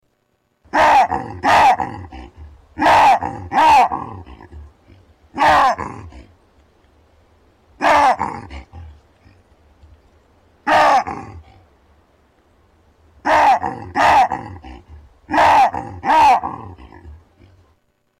На этой странице собраны разнообразные звуки бабуина — от громких криков до ворчания и общения в стае.
Громкий крик взрослого самца бабуина